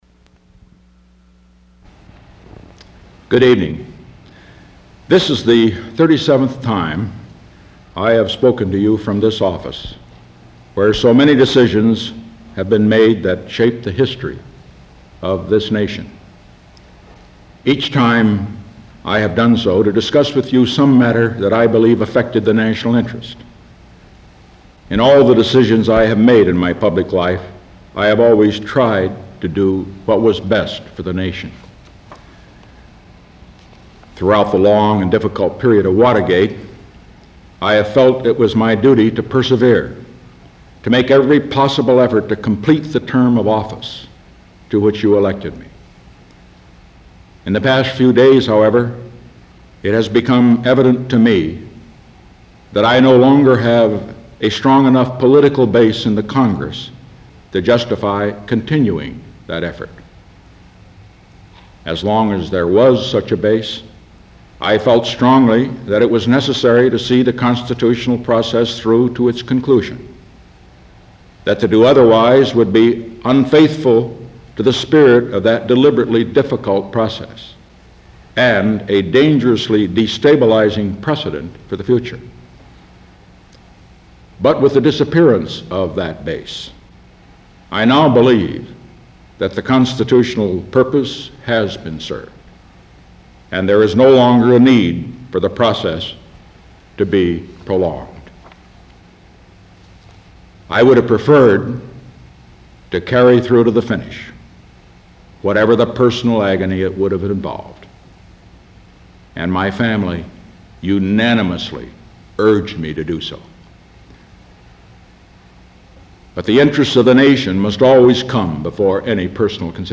August 8, 1974: Address to the Nation Announcing Decision To Resign the Office of President